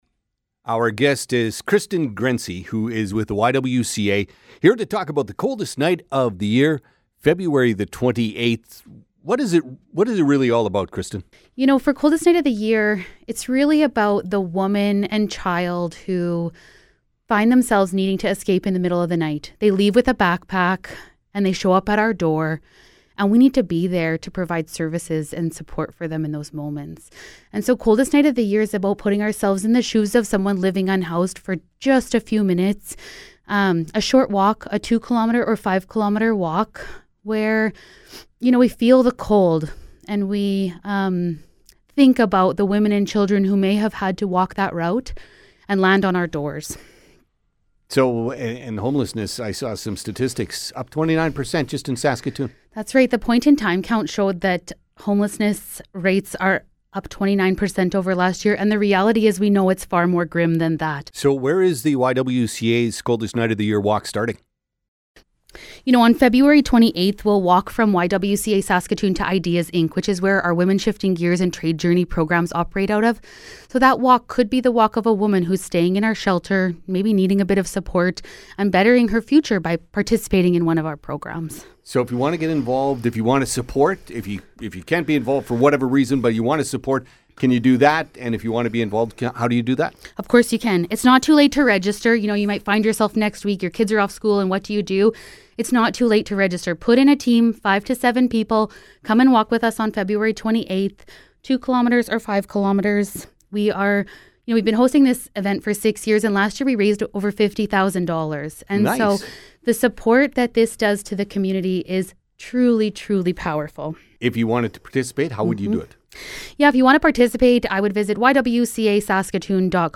ywca-coldest-night-of-the-year-interview.mp3